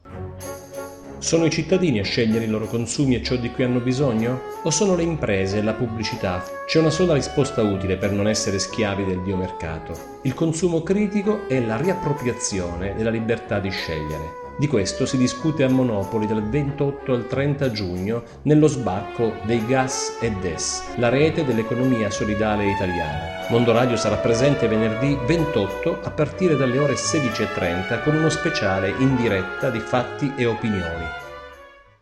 Documenti allegati: Promo della puntata